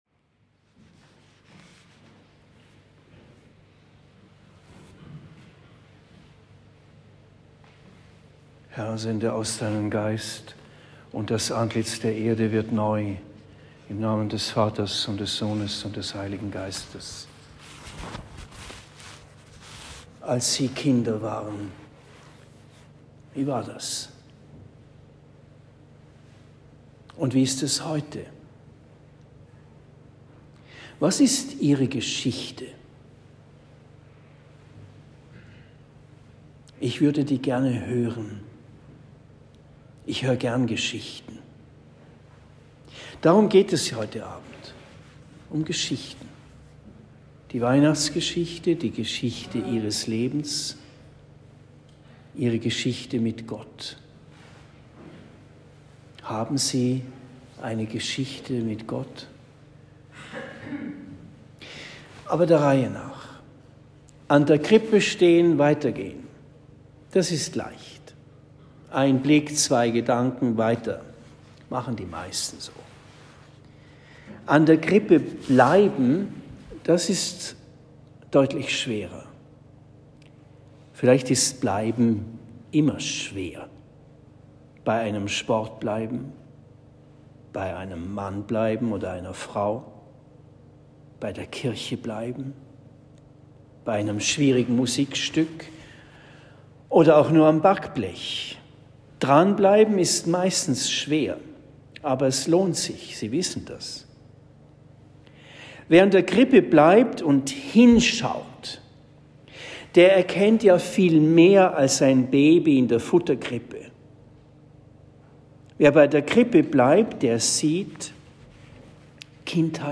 24. Dezember 2022 - Christmette in Marienbrunn